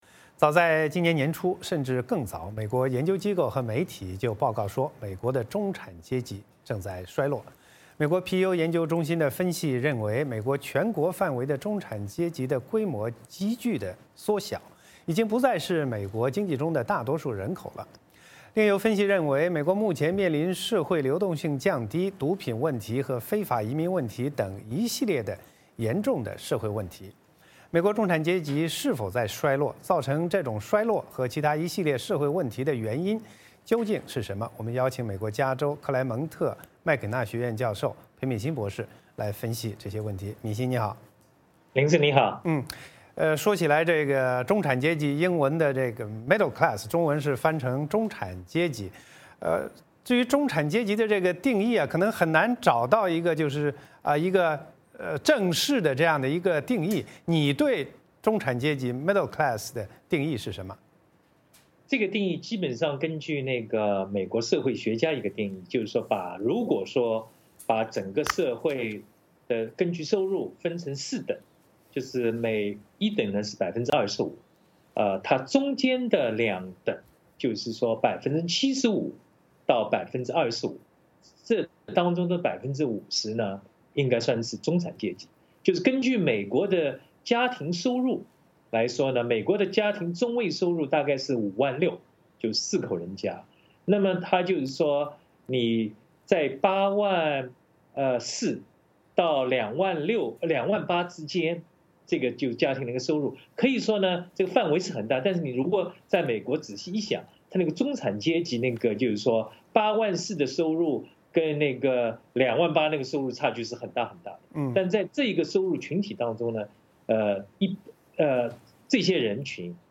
美国中产阶级是否在衰落？造成这种衰落和其它一系列社会问题的原因是什么？我们邀请美国加州克莱蒙特·麦肯纳学院教授裴敏欣博士来分析这些问题。